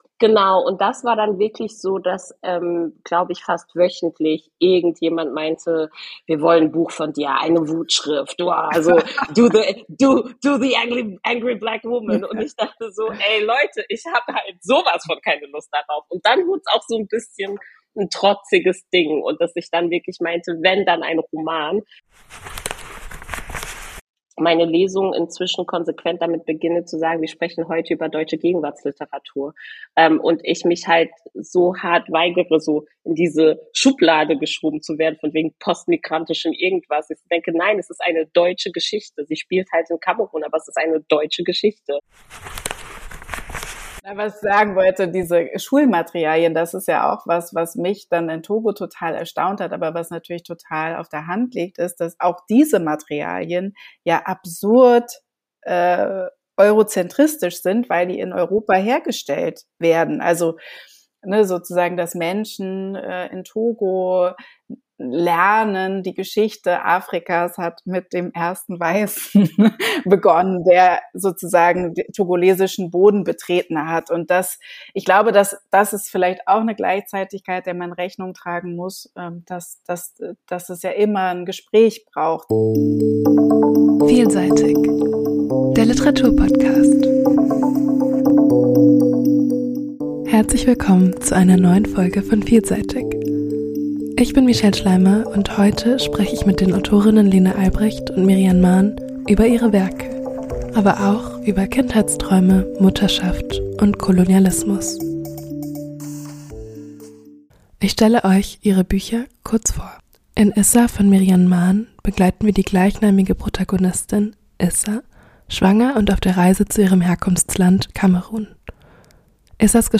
In diesem Interview-Projekt lade ich Autor:innen und Personen aus dem Literaturbetrieb ein, um mit ihnen über ihre Werke und ihre Arbeit zu sprechen. Dabei möchte ich vor allem jungen (post-)migrantischen Menschen einen Einblick geben und sie empowern.